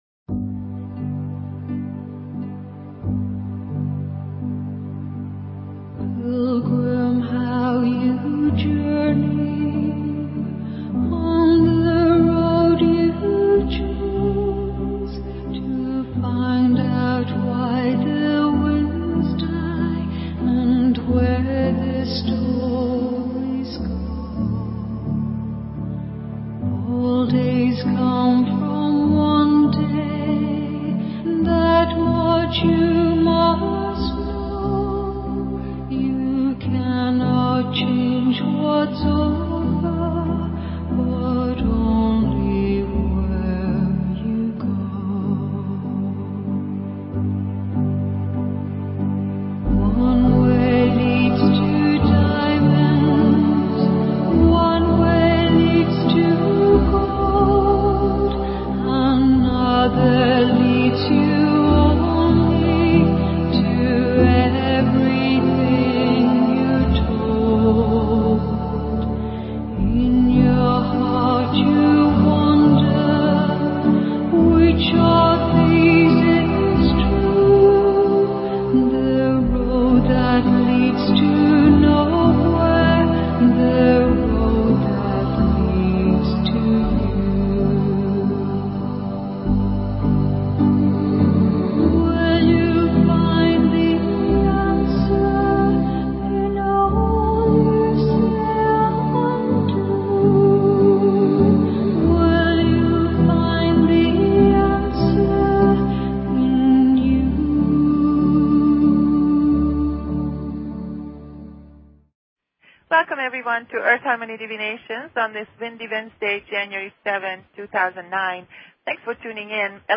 Talk Show Episode, Audio Podcast, Earth_Harmony_Divinations and Courtesy of BBS Radio on , show guests , about , categorized as